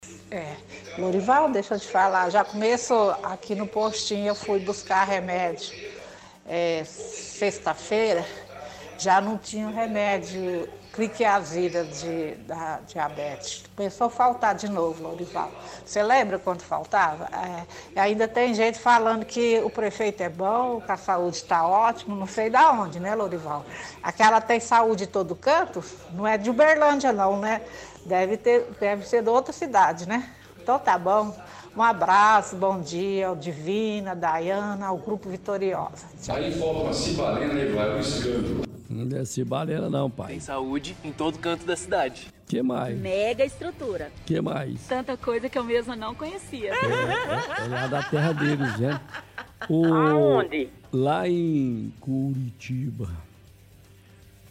– Ouvinte reclama que não há medicamentos suficientes no postinho de saúde para tratamento de diabetes.
– Caçoa do prefeito Odelmo com áudios antigos e das propagandas.